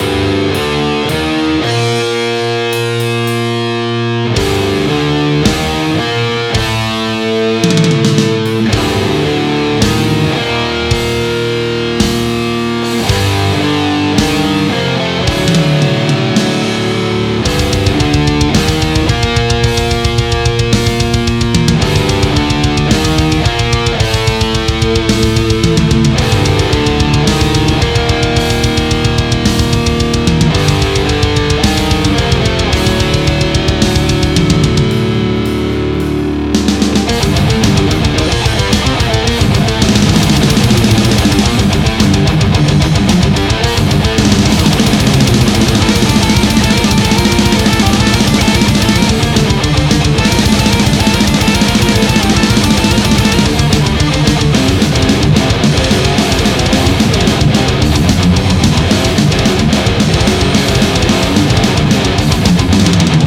Prog death metal ������� (SSD+Le456+TRacks) ���� ��������� ������?